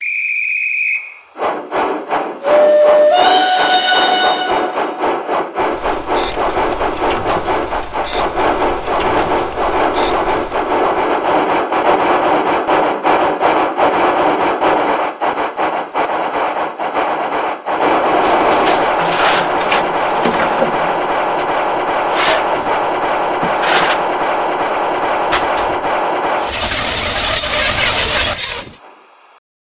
Märklin 37191 Demo-Sound.mp3